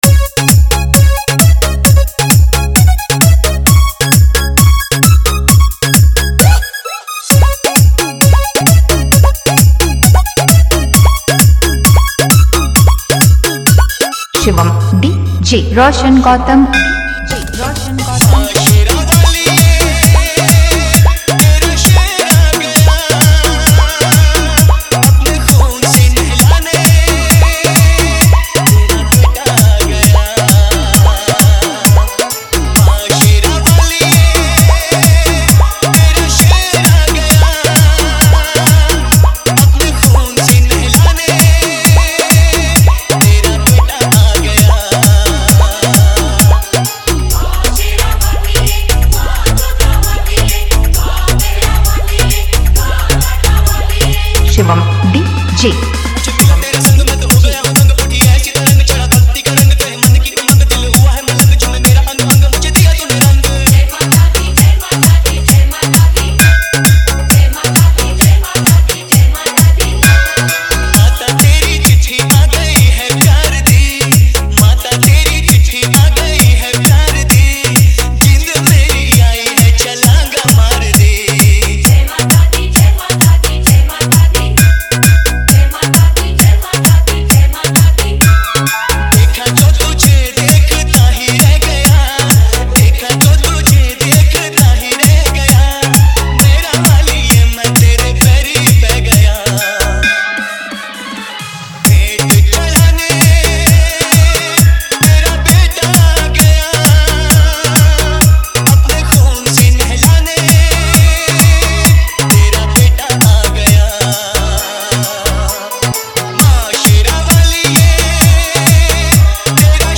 Bhakti Dj Remix Songs 2025